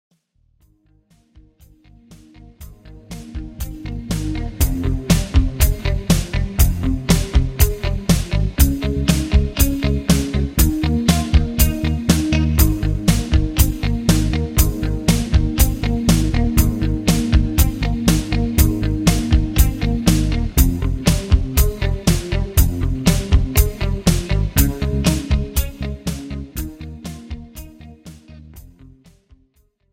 This is an instrumental backing track cover.
• Key – A♭
• With Backing Vocals
• With Fade